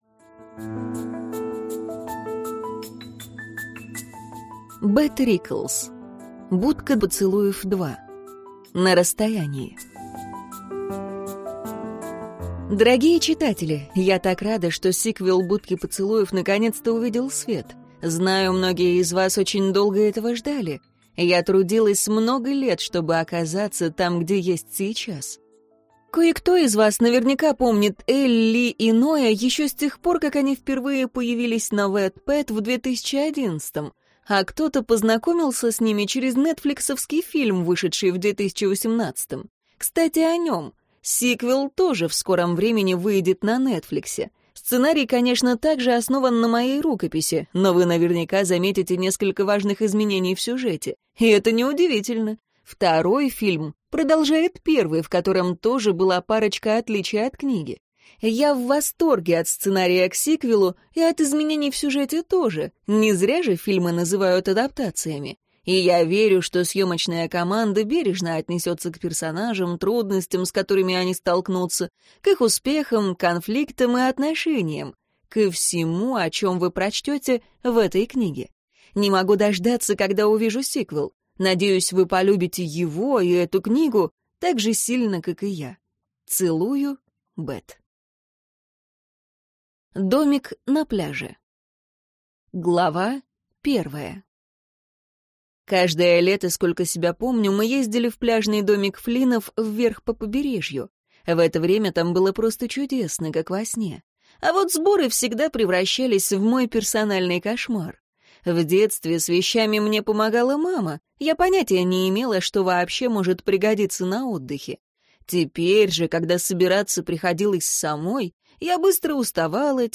Аудиокнига Будка поцелуев 2. На расстоянии | Библиотека аудиокниг